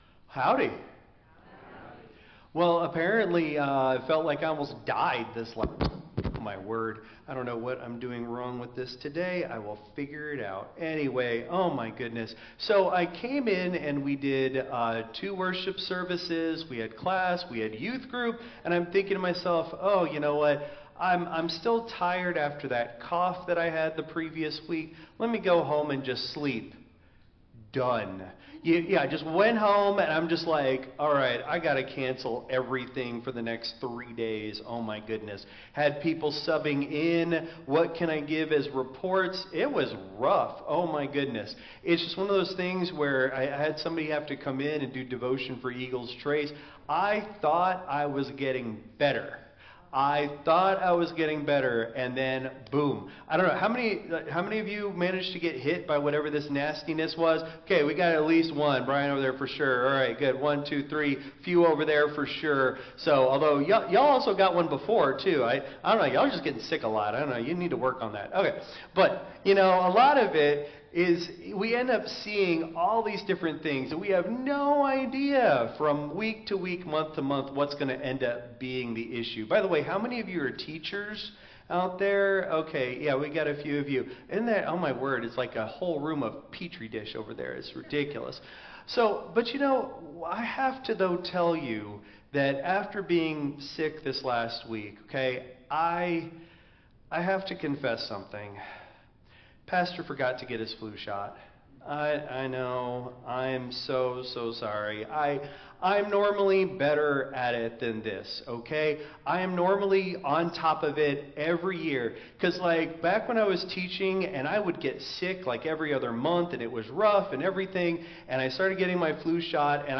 audio of Sunday sermons